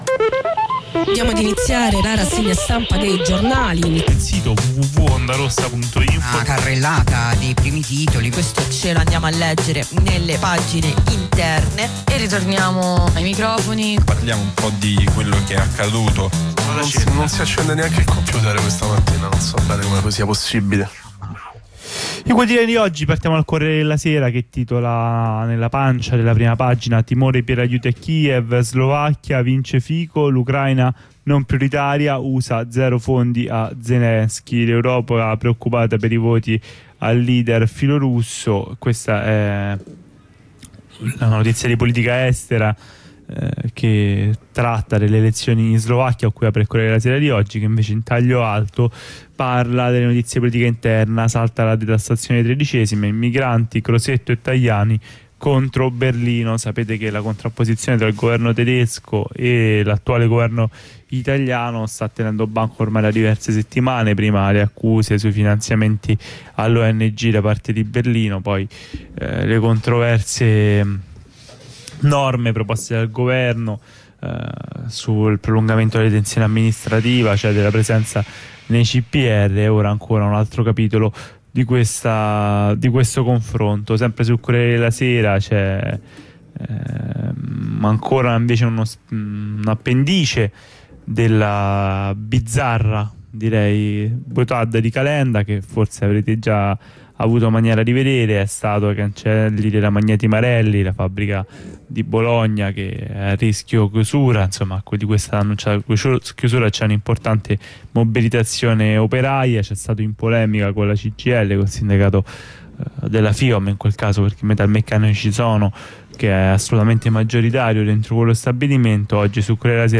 Rassegna stampa - 2 ottobre
La lettura dei quotidiani di oggi